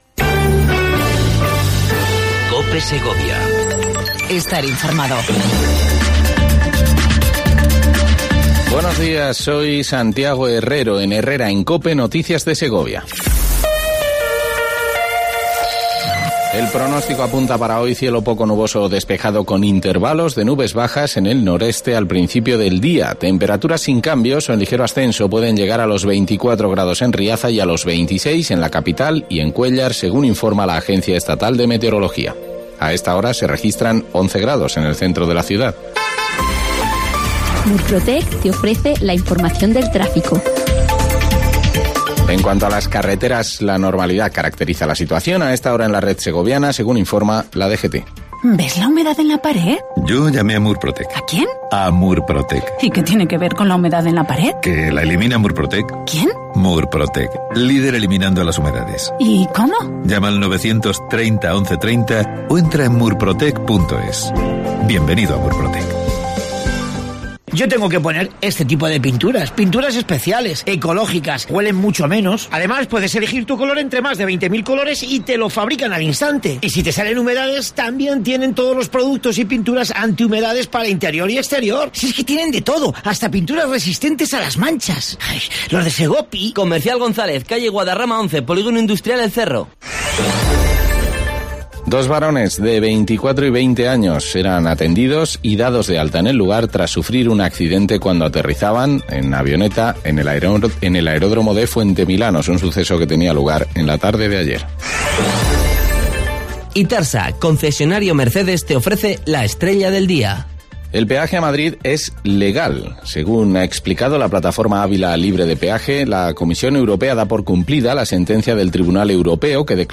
AUDIO: Segundo informativo local en cope segovia 04/10/18